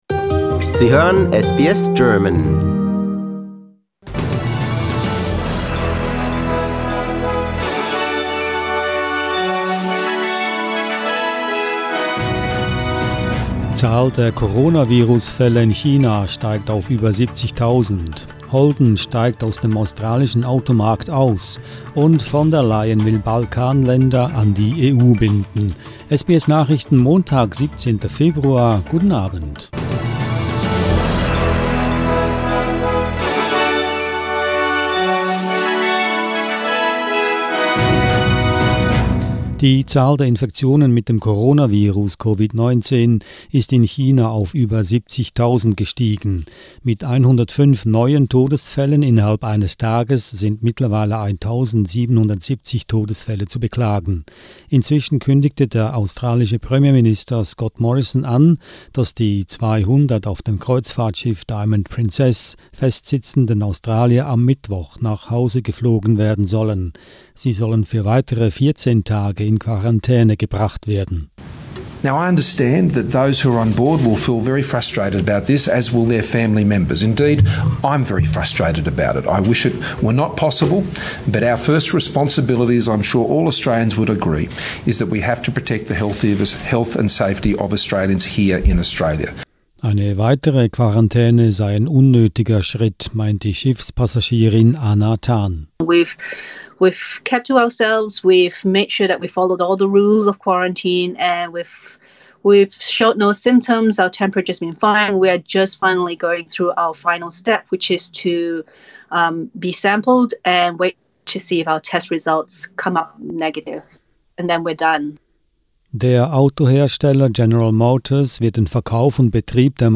SBS Nachrichten, Montag 17.02.20